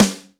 SUVIV SD.wav